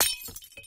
default_break_glass.3.ogg